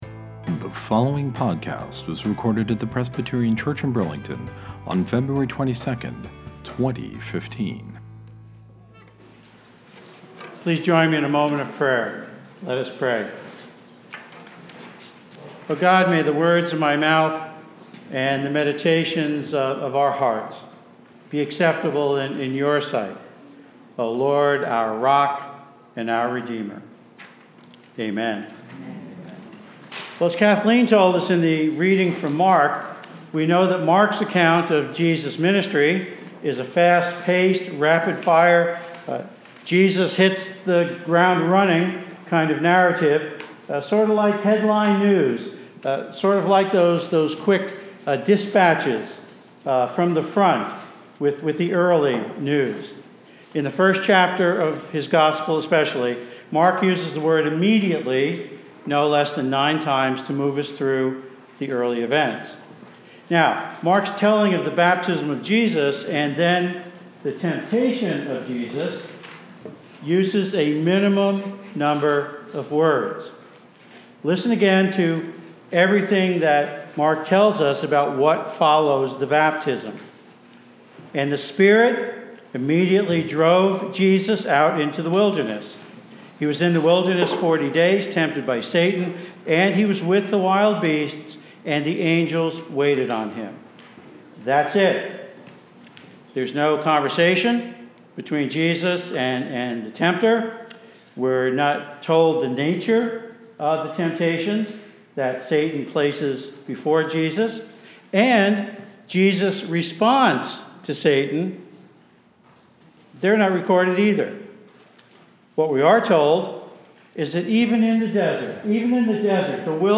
This Sunday, the First Sunday in Lent, I’ll be preaching on Jesus’ temptation as we find it recorded in Mark’s gospel. Mark’s account is very short, unlike Luke and Matthew’s accounts, which provide much more dialogue between Jesus and the tempter.